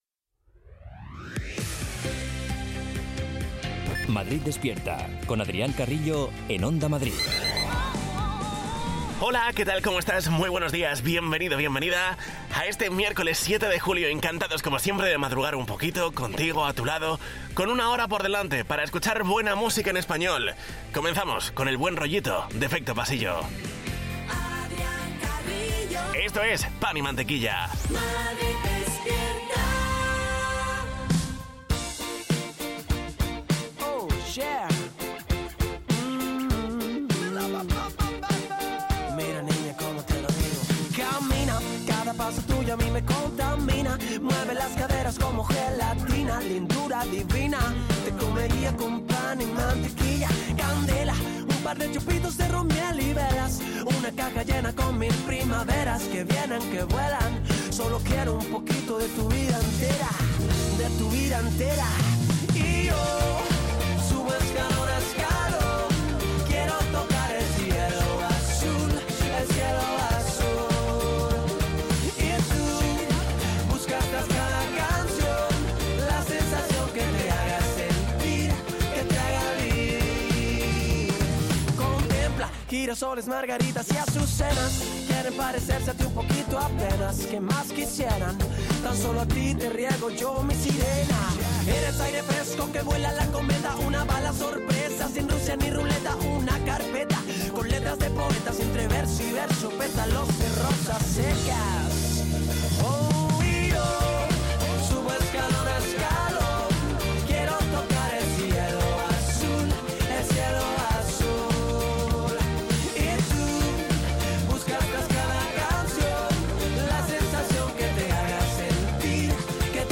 Morning show para despertar a los madrileños con la mejor música y la información útil para afrontar el día.